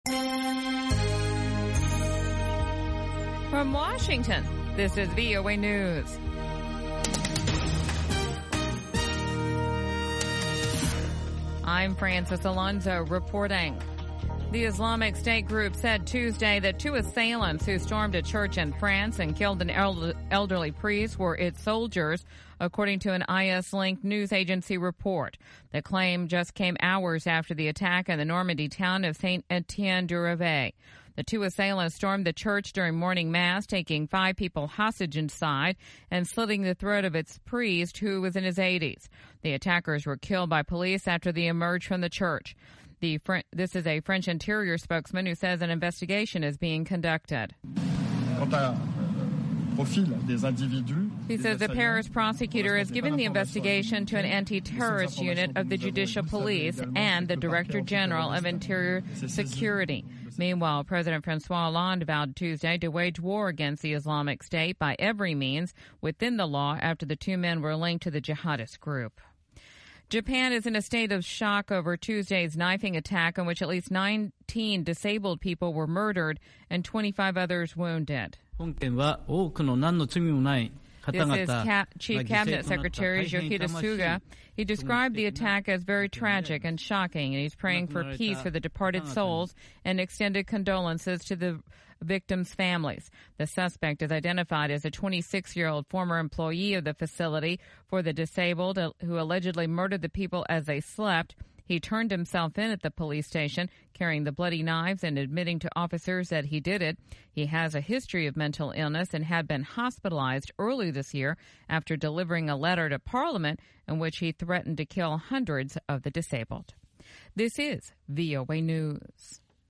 1400 UTC Newscast for July 26